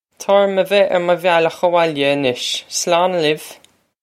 Pronunciation for how to say
Taw orr-um veh air muh vyal-okh a-wohl-ya ah-nish. Slawn lee-iv!